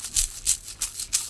Closed Hats
07_Hats_20_SP.wav